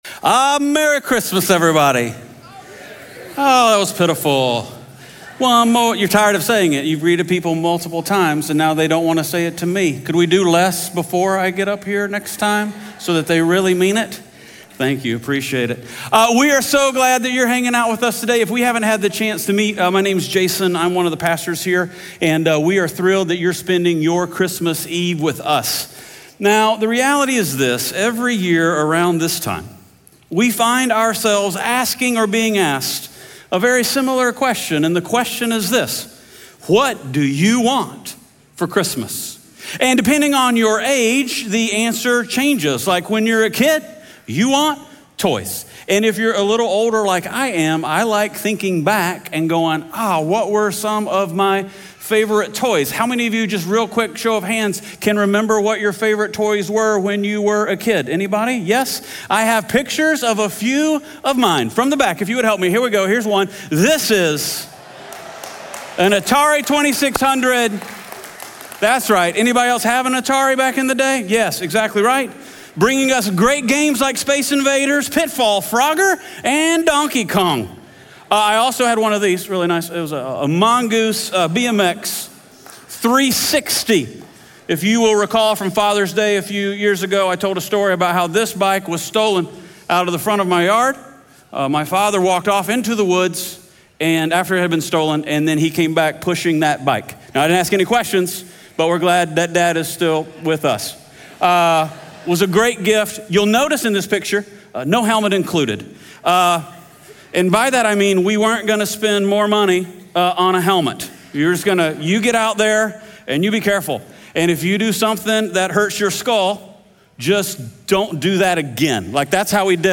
Audio messages from Fellowship Greenville in Greenville, SC